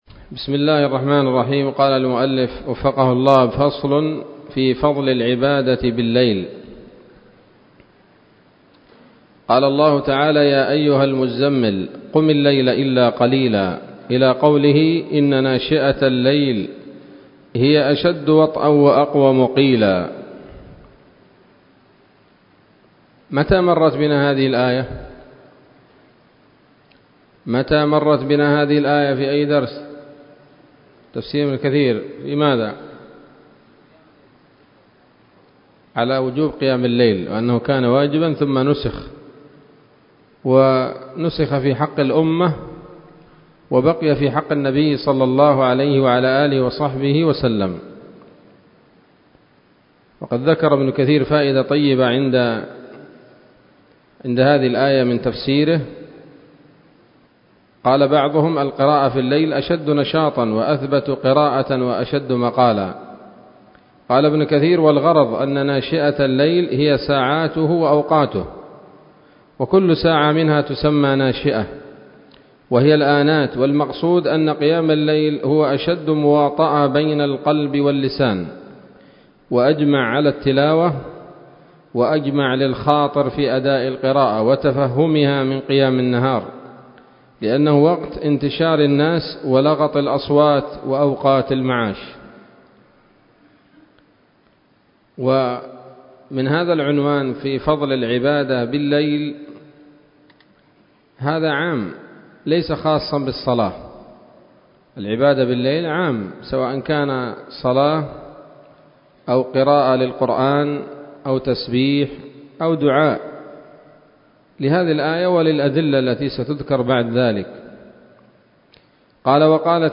الدرس الحادي والعشرون من رياض الأبرار من صحيح الأذكار